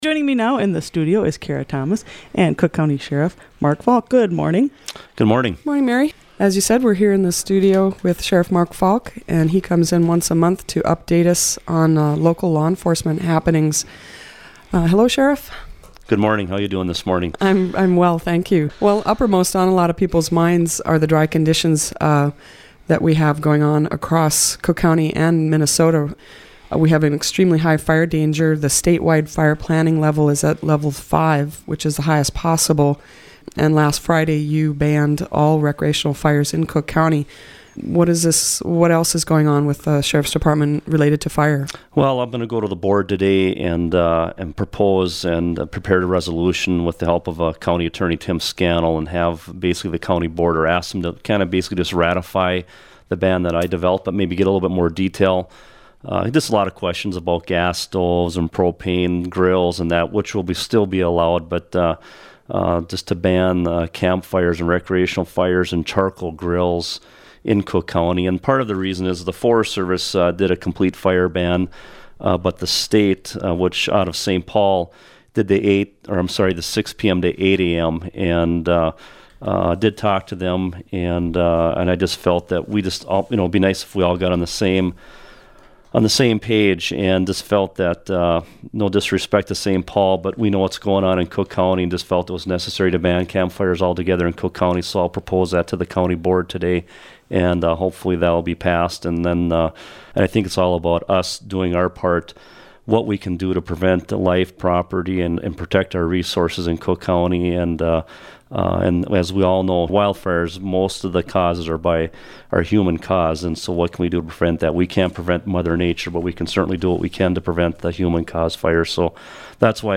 Each month, WTIP interviews Cook County Sheriff Mark Falk about current issues facing local law enforcement .